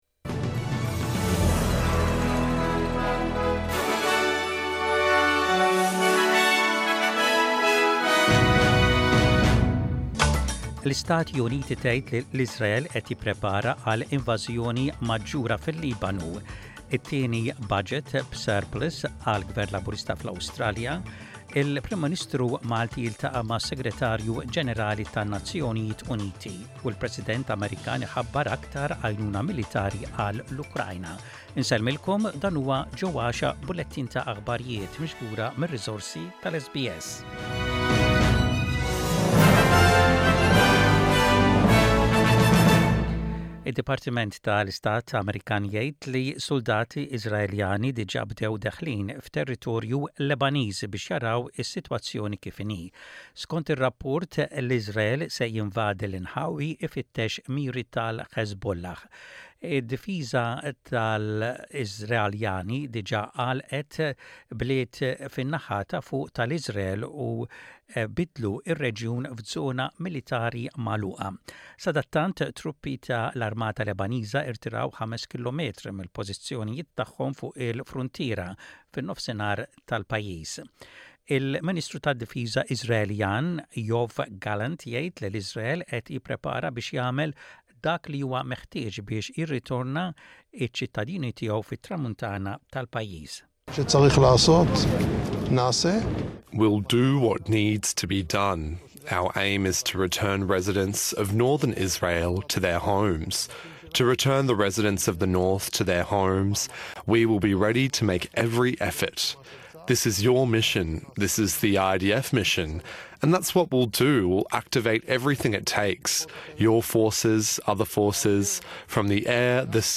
SBS Radio | Aħbarijiet bil-Malti: 01.10.24